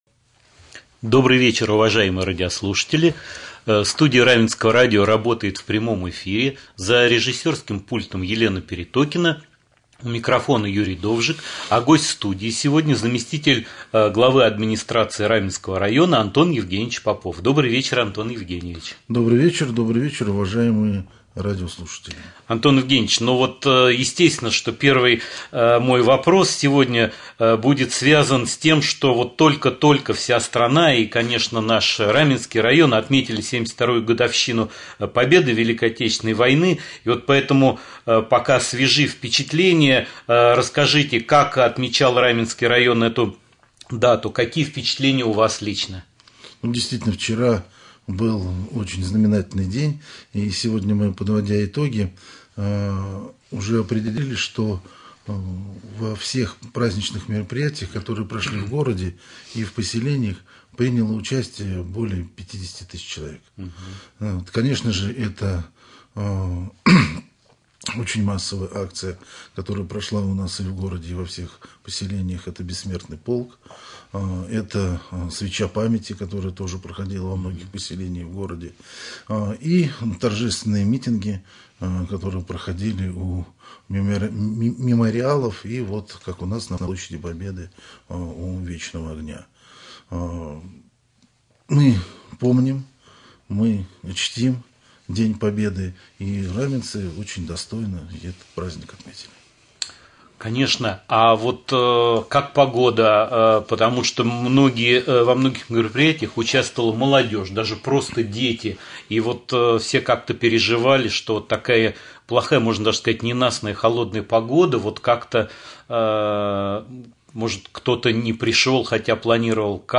Прямой эфир. Гость студии заместитель главы администрации Раменского района Антон Евгеньевич Попов.